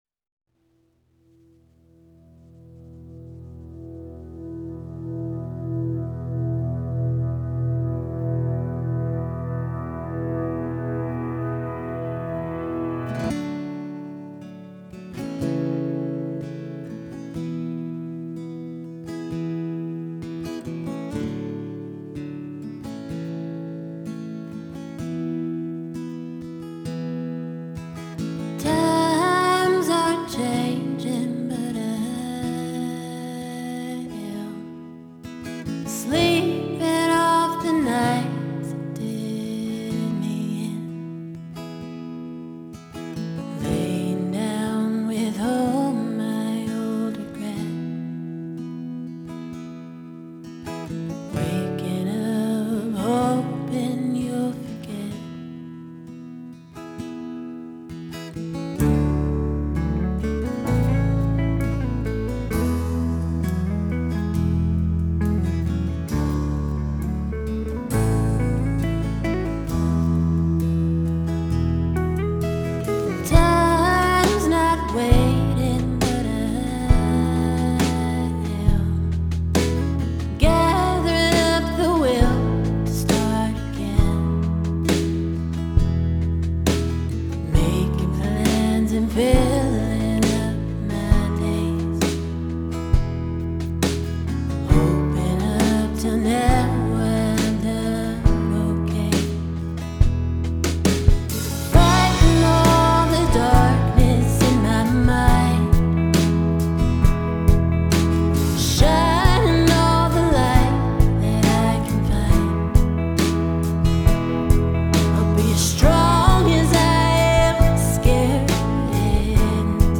Genre: Folk Pop, Americana, Singer-Songwriter